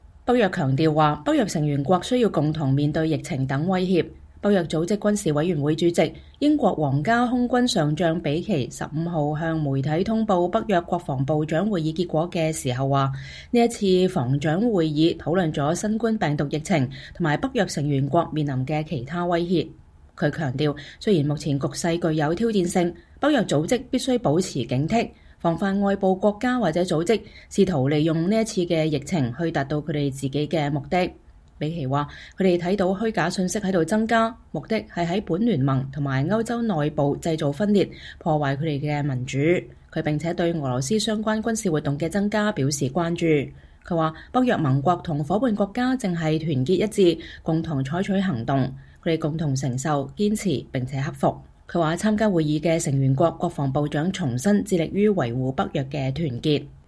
北约组织军事委员会主席、英国皇家空军上将彼奇（Stuart Peach）15日向媒体通报北约国防部长会议结果。